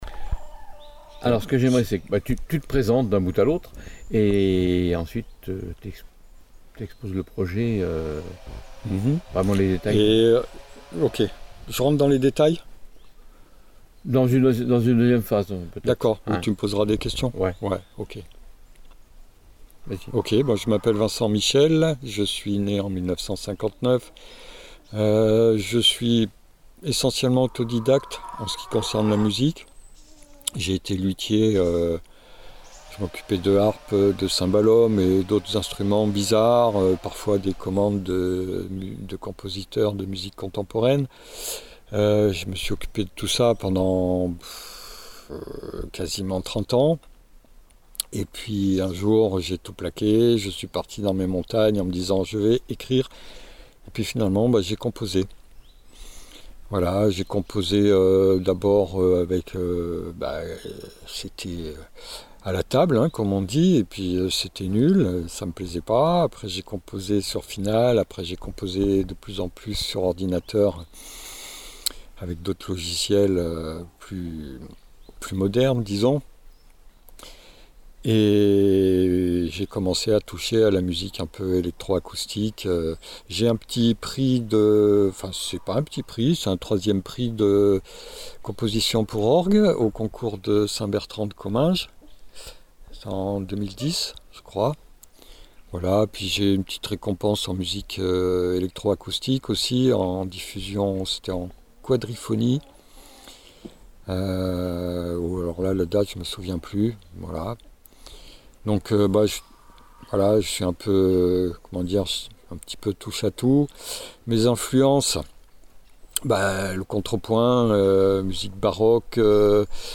interview
(avec poules, tronçonneuse…)